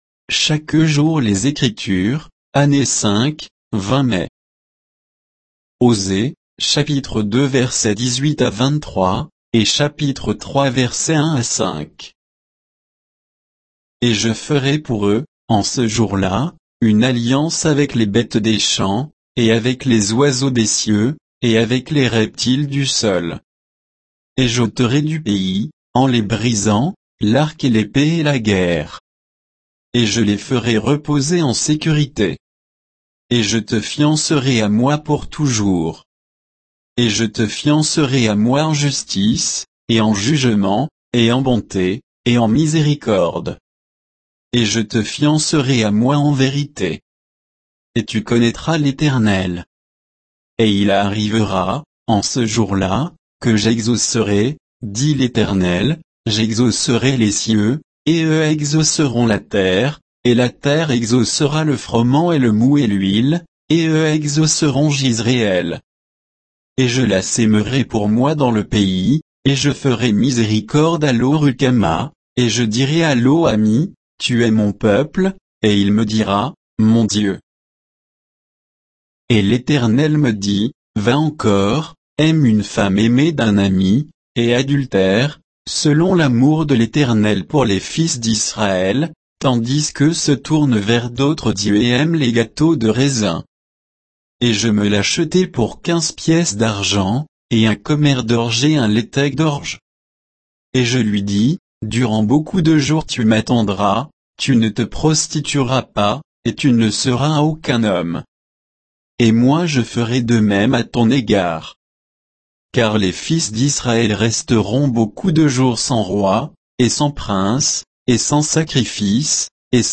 Méditation quoditienne de Chaque jour les Écritures sur Osée 2